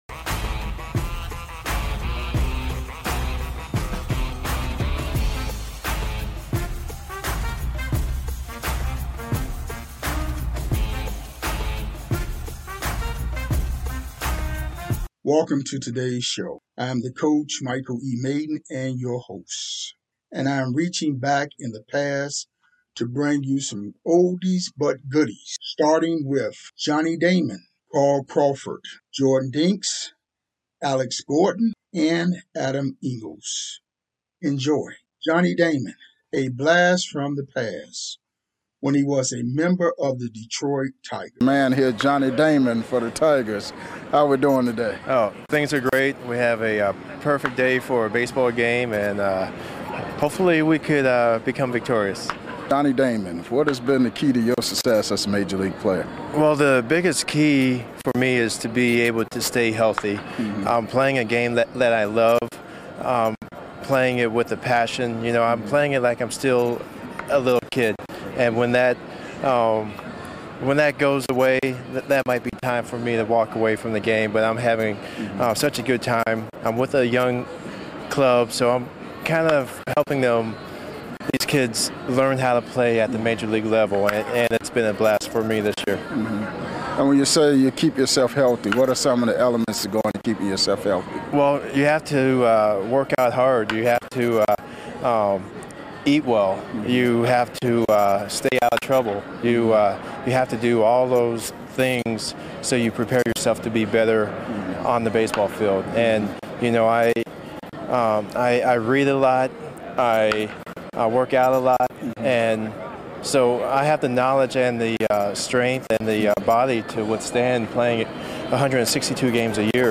Classic MLB Interviews